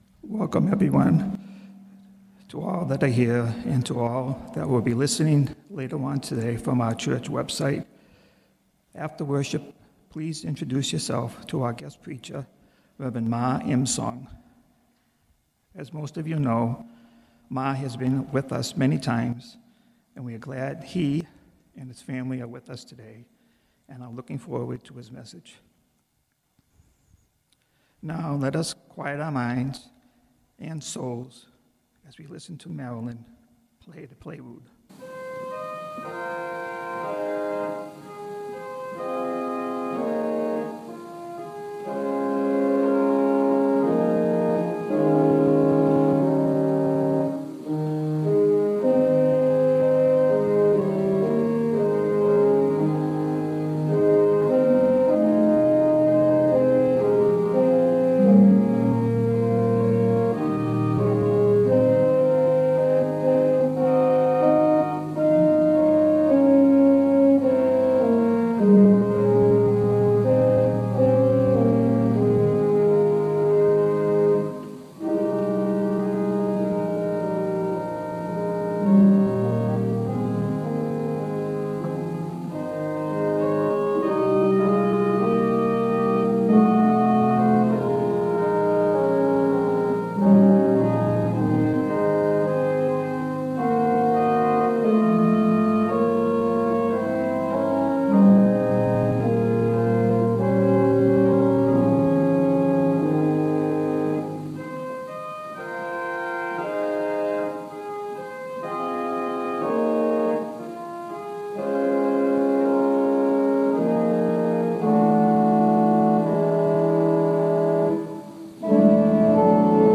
Worship Service September 26, 2021 | First Baptist Church, Malden, Massachusetts
Prelude
Call to Worship Invocation Praise and Worship
at the piano
Hymn
Sermon
Postlude